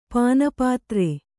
♪ pāna pātre